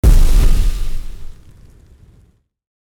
spell-impact-6.mp3